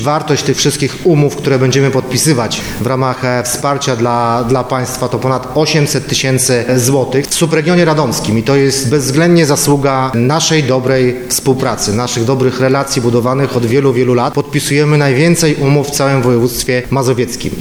Jak układa się współpraca samorządu z organizacjami pozarządowymi mówi Wicemarszałek Województwa Mazowieckiego Rafał Rajkowski: